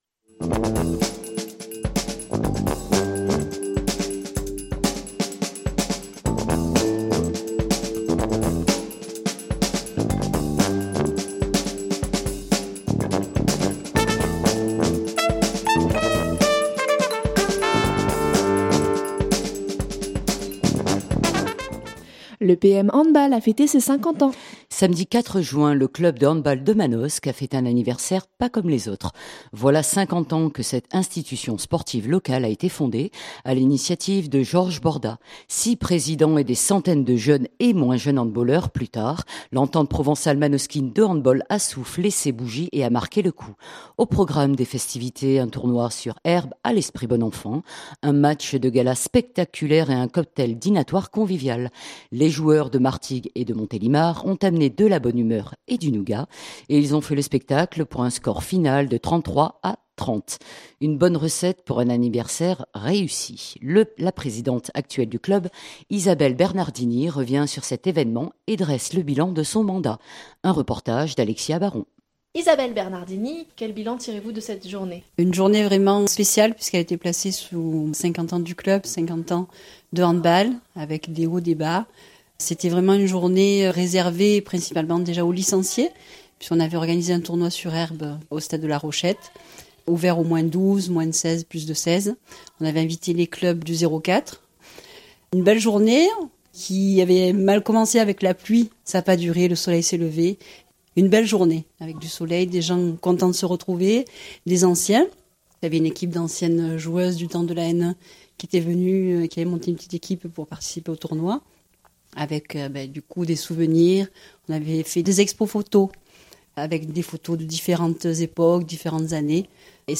Un reportage